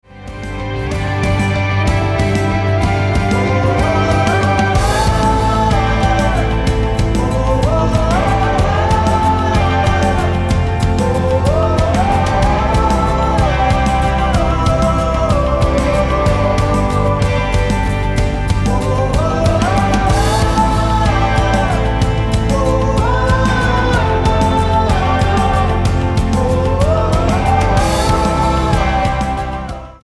Tonart:C mit Chor
Die besten Playbacks Instrumentals und Karaoke Versionen .